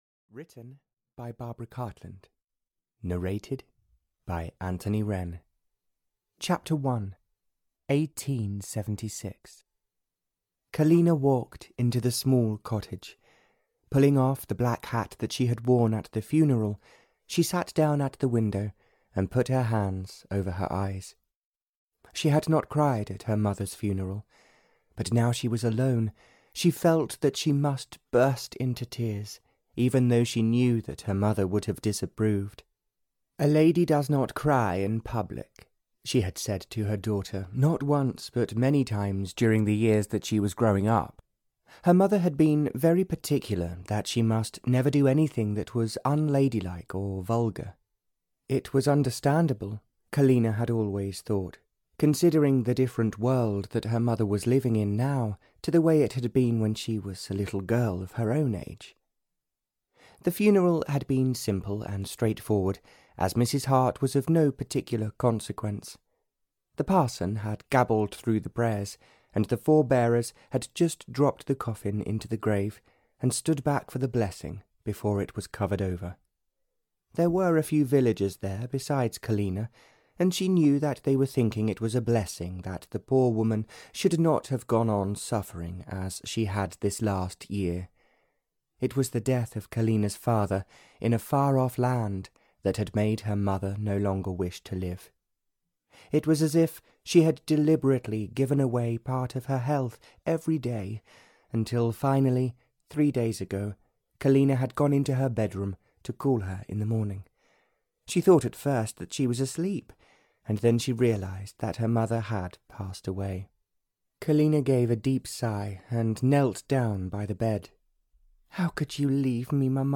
No Bride, No Wedding (Barbara Cartland's Pink Collection 133) (EN) audiokniha
Ukázka z knihy